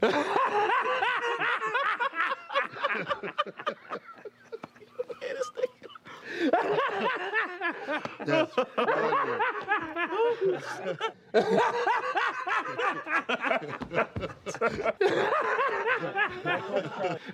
PLAY LAUGHING123
Play, download and share LAUGHING123 original sound button!!!!